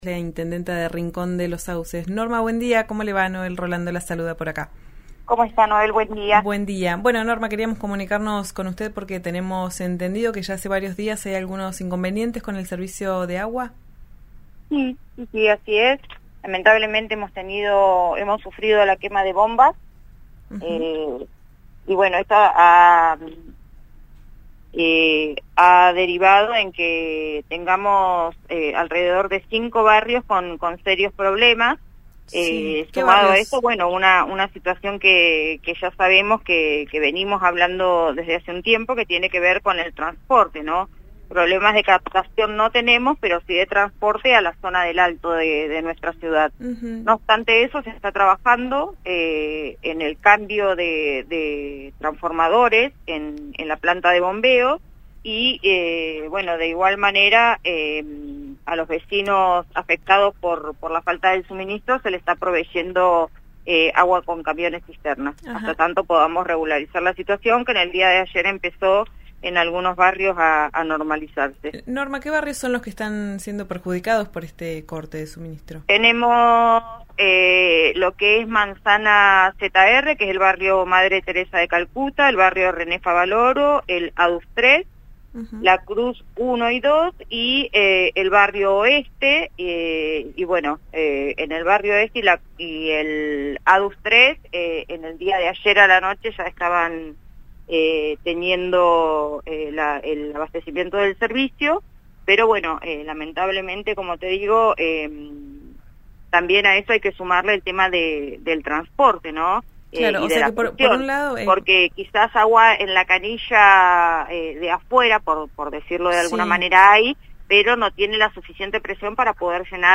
Escuchá a la intendenta de Rincón de los Sauces, Norma Sepúlveda, en diálogo con «Ya es tiempo» por RÍO NEGRO RADIO: